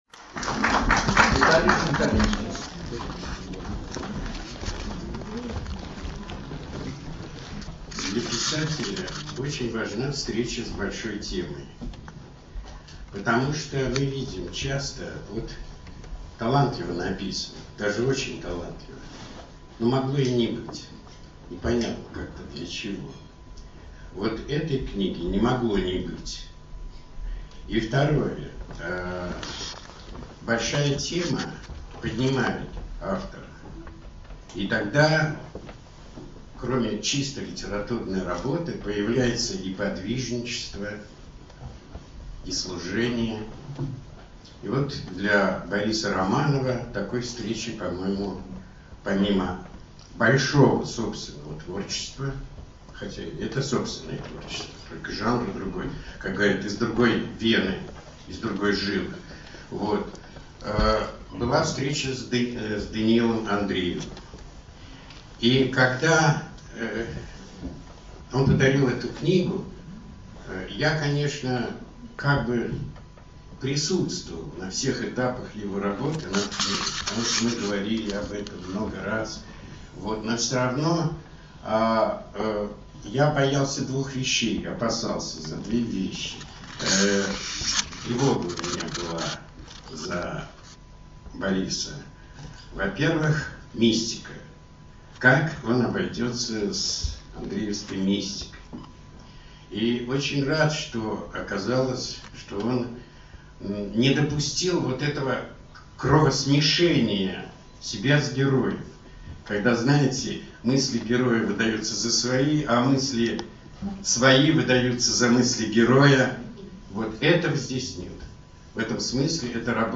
в ЦДЛ 16 мая 2011 г. (аудиозапись выступлений в mp3)